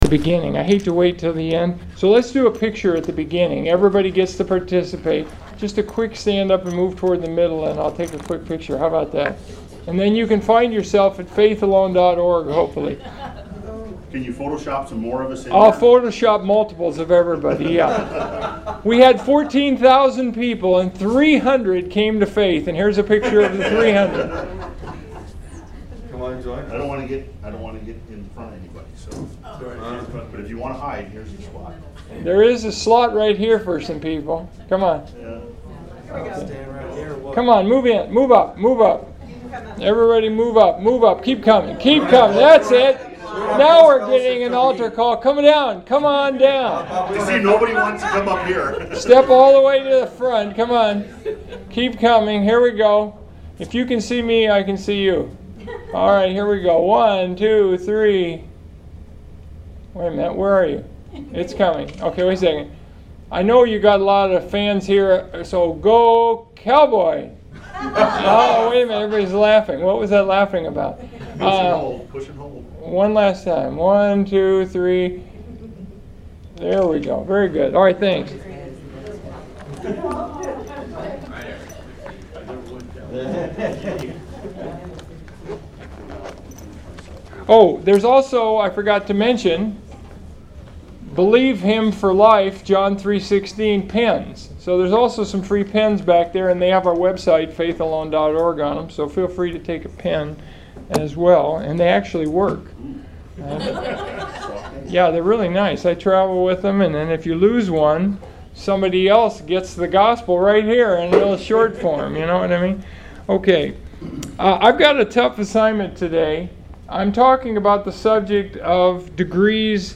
Trinity Baptist Church, Biloxi, MS